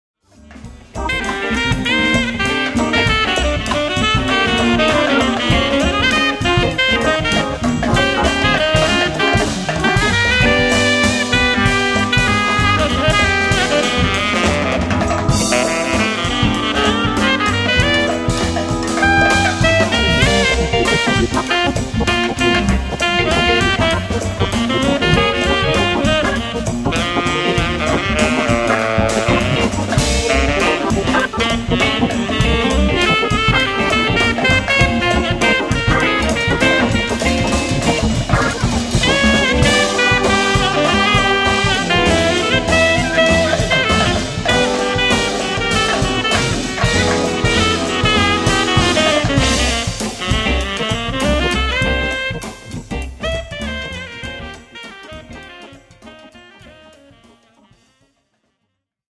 keyboards
acoustic bass
drums and percussion
saxophone